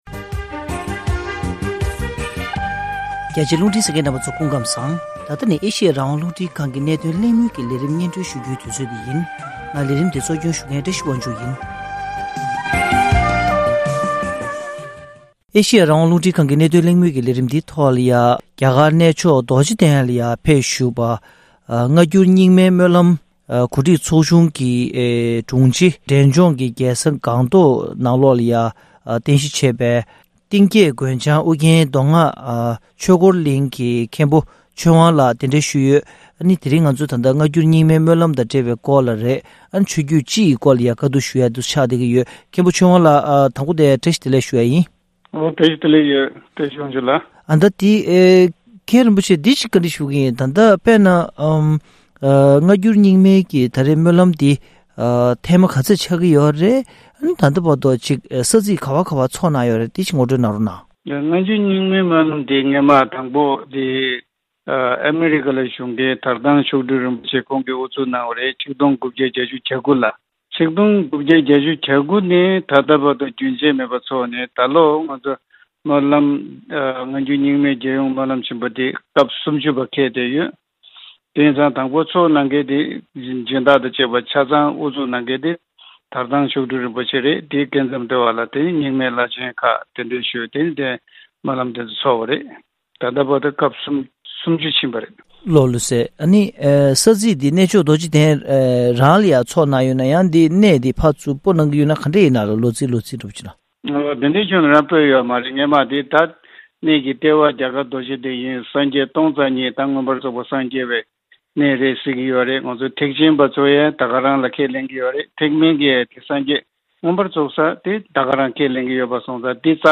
༄༅།།ཐེངས་འདིའི་གནད་དོན་གླེང་མོལ་གྱི་ལས་རིམ་ནང་།